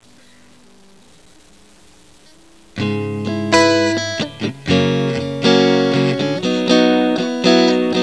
自宅の性能のくそ悪いパソコンで撮ったのでこんな音になってしまいました。